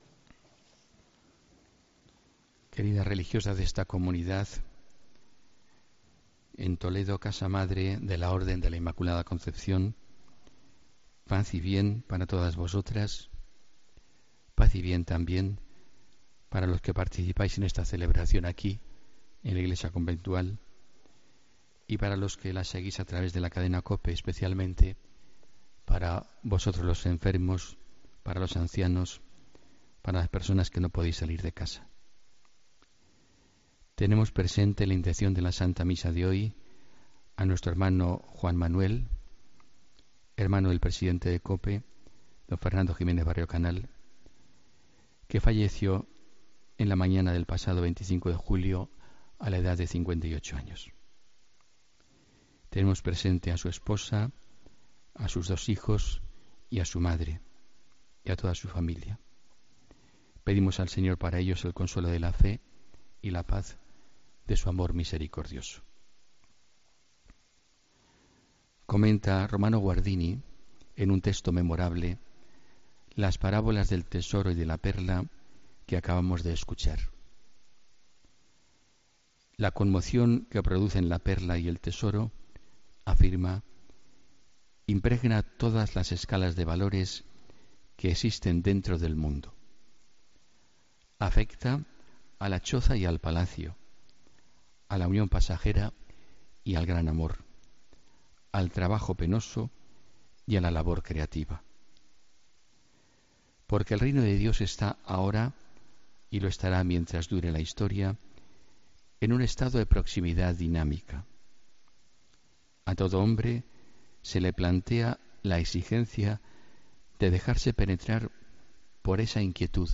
Homilía del domingo 30 de julio de 2017